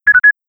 3. Triple Beep (
triplebeep.wav